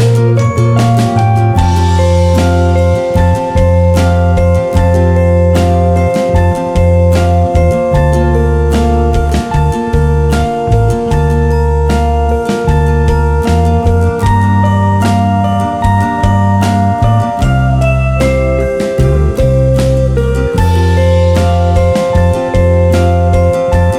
no Backing Vocals Indie / Alternative 3:49 Buy £1.50